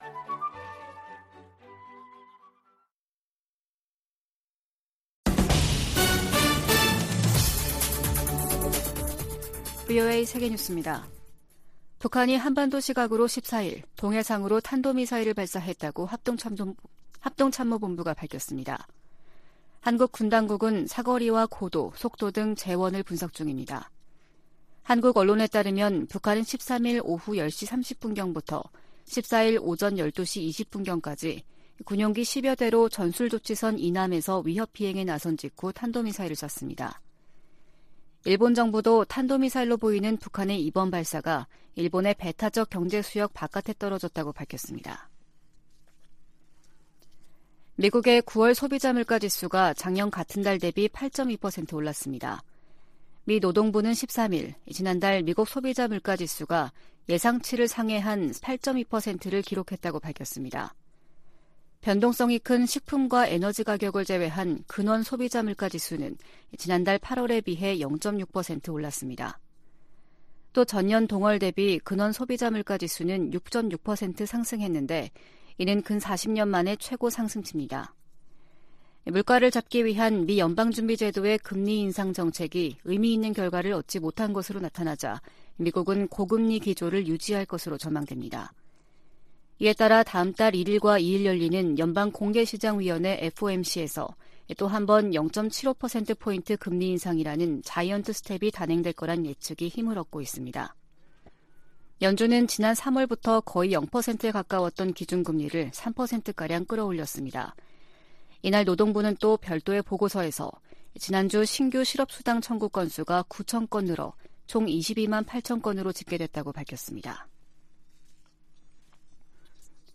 VOA 한국어 아침 뉴스 프로그램 '워싱턴 뉴스 광장' 2022년 10월 14일 방송입니다. 북한이 핵운용 장거리 순항 미사일을 시험발사했습니다.